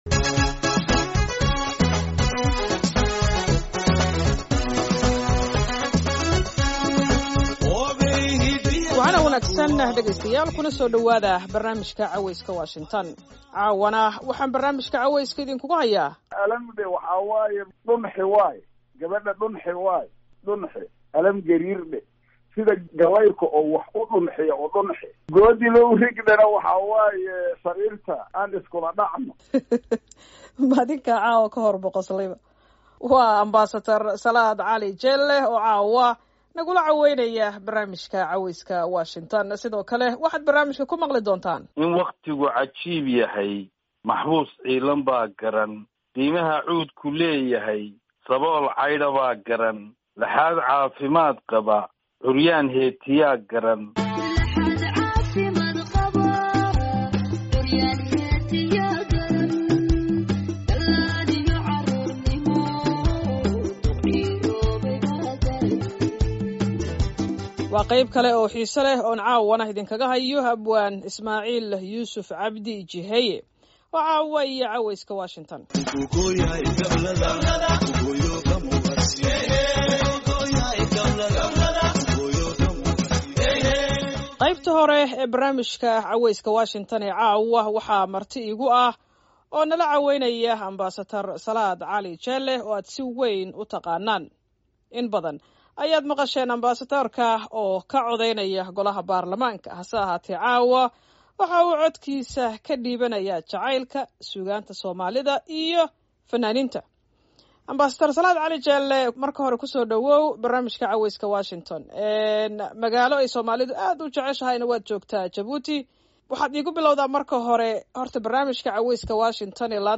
Qodobada aad ku maqli doontaan waxaa ka mid wareysi uu barnaamijka Caweyska Washington siiyay Danjire Salaad Cali Jeelle oo ka hadlaya sheekooyin xiisa leh.